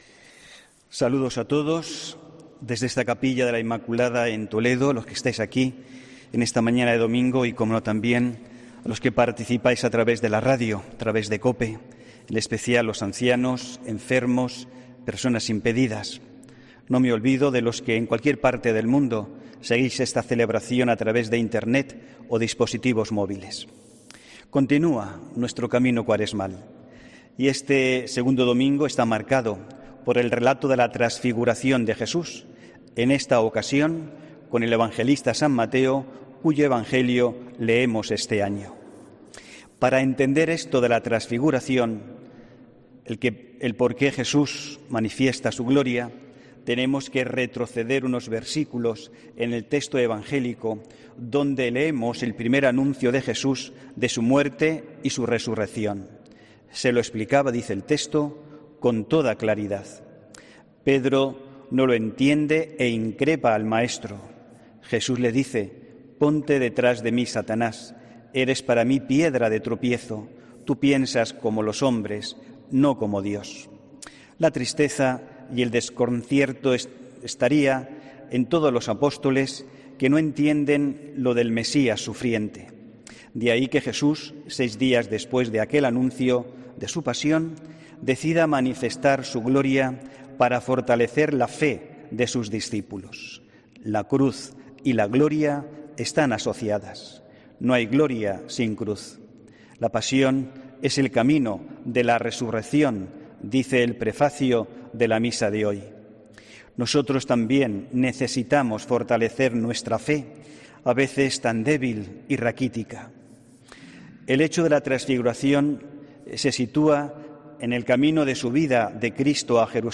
HOMILÍA 8 MARZO 2020